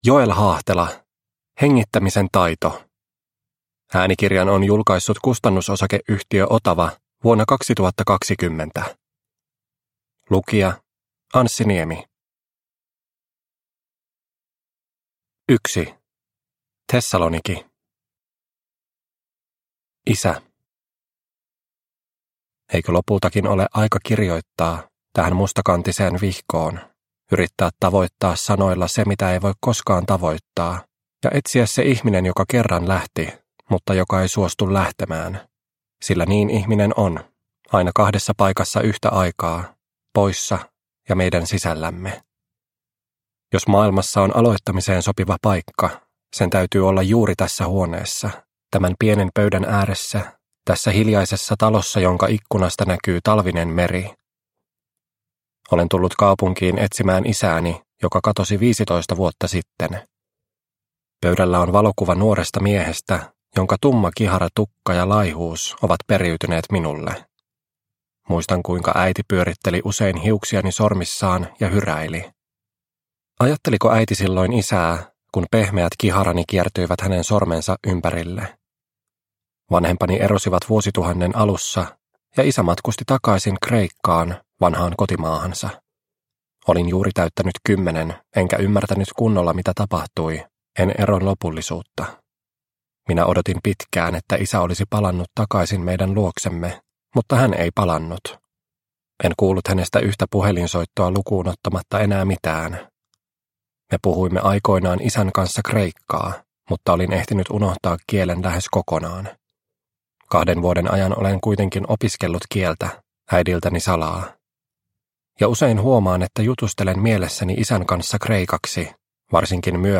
Hengittämisen taito – Ljudbok – Laddas ner